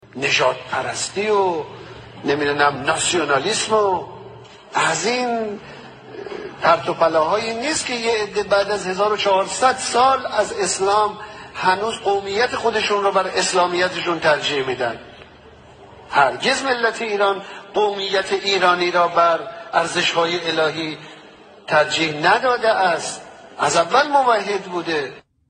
ahmadinejad-nationalism-islamism.mp3